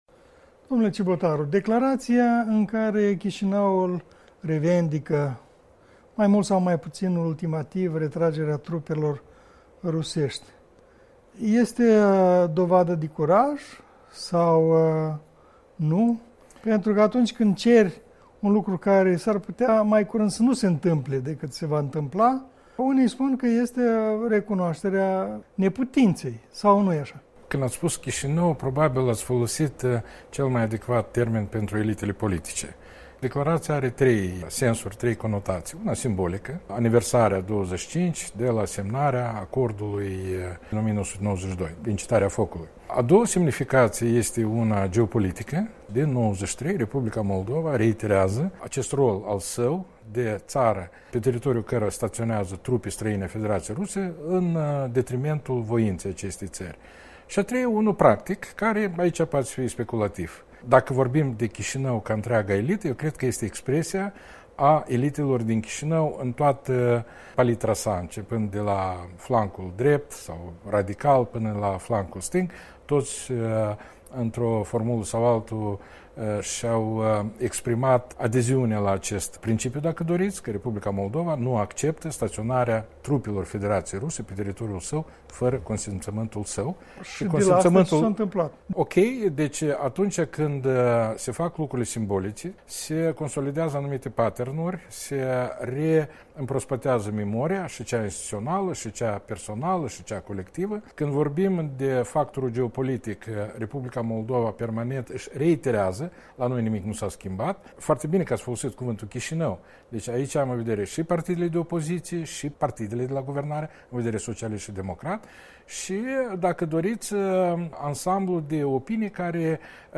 Despre ultimele evoluții în chestiunea transnistreană în dialog cu fostul ministru moldovean al apărării.